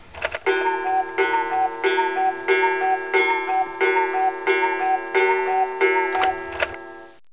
Alarmerinnerung
cuckoo2.JZVWOTyi.mp3